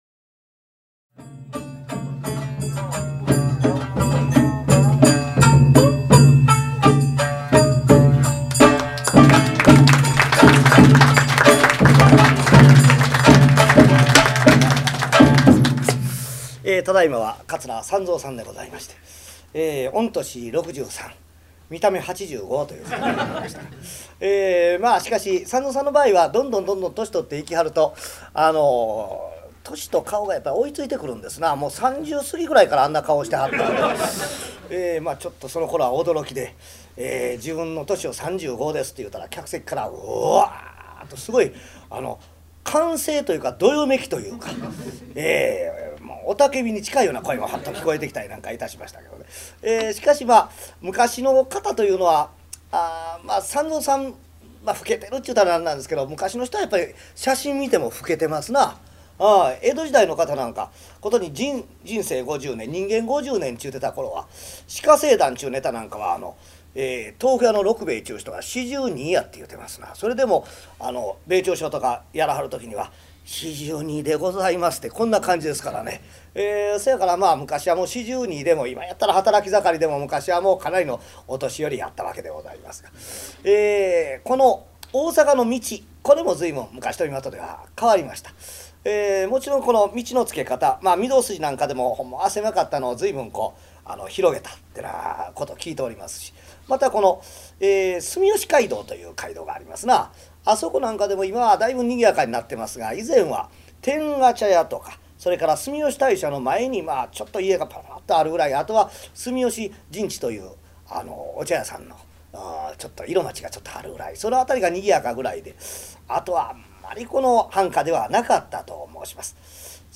「猫間川寄席」の雰囲気を、そのままで！
約19年間、大阪玉造さんくすホールで、毎月開催されている「猫間川寄席」での、四代目桂文我の口演を収録した落語集。 書籍版「桂文我 上方落語全集」に掲載したネタを、ライブ公演の録音で楽しみ、文字の落語と、実際の口演との違いを再確認していただければ幸いです。